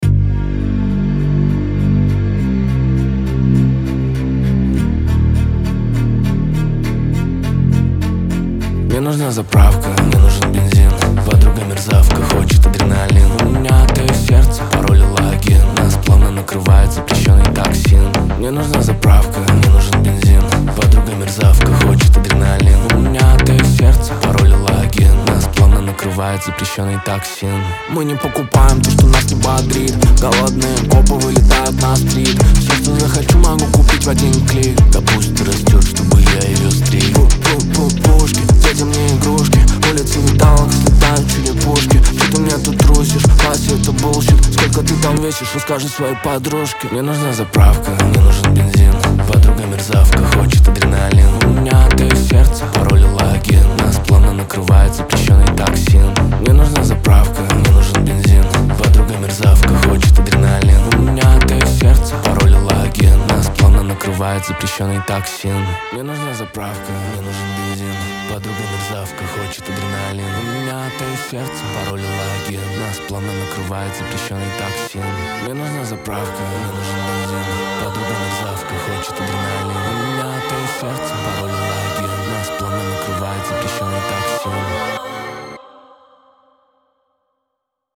ХАУС-РЭП